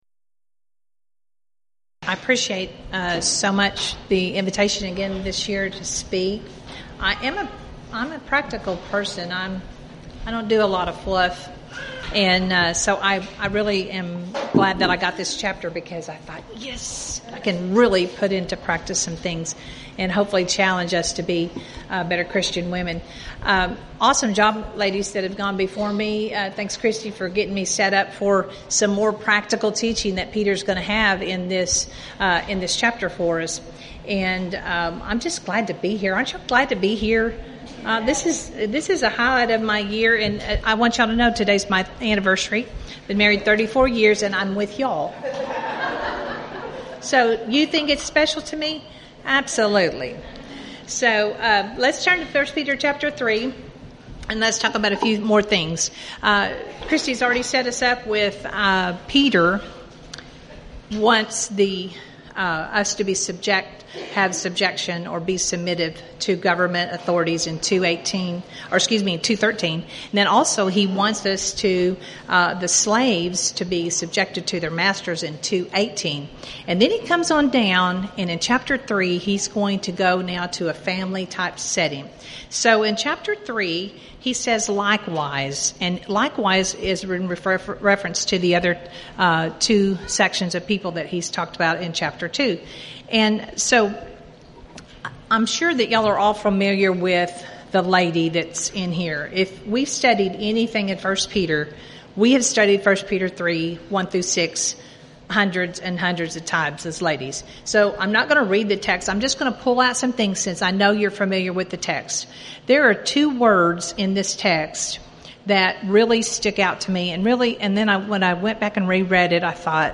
Event: 3rd Annual Texas Ladies in Christ Retreat Theme/Title: Studies in I Peter
lecture